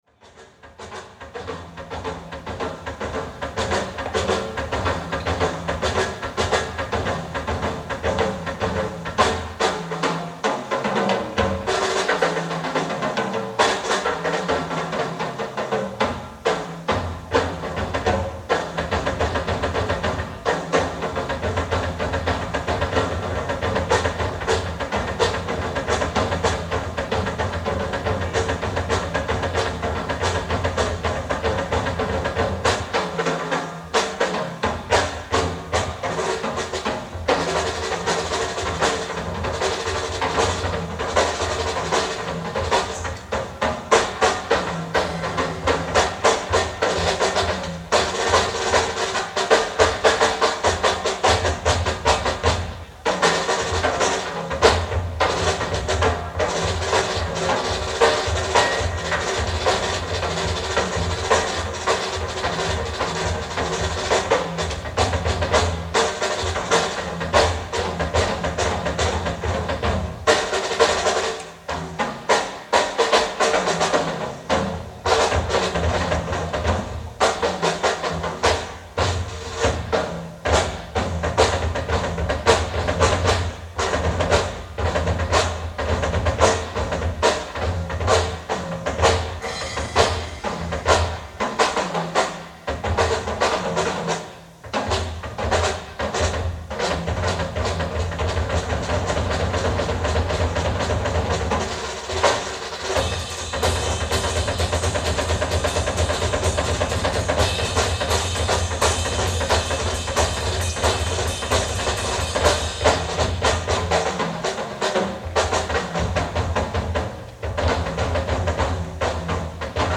ASSORTED DRUMS SOLOS (1967-1972) mp3
Don´t expect stereo hifi sound.
* Liseberg, Gothenburg, Sweden, September 1st 1970